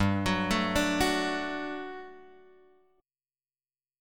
GM7sus2 chord {3 x 4 2 3 2} chord